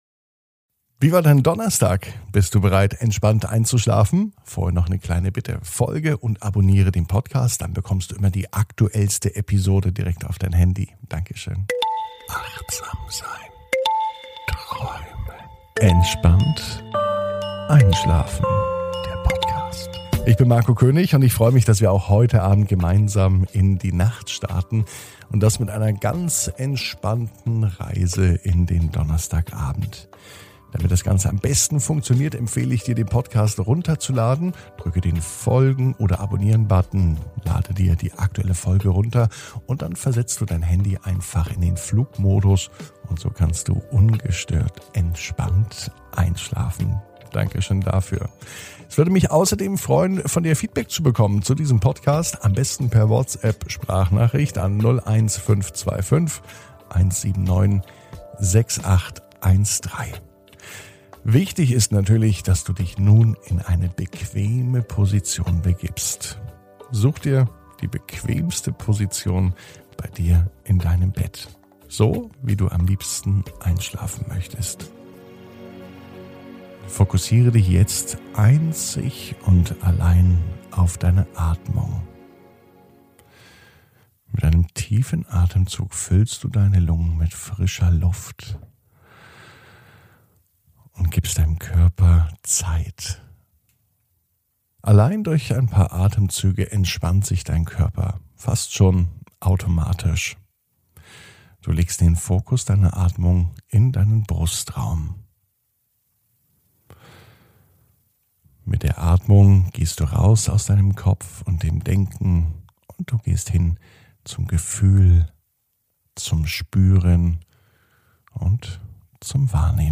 (ohne Musik) Entspannt einschlafen am Donnerstag, 22.04.21 ~ Entspannt einschlafen - Meditation & Achtsamkeit für die Nacht Podcast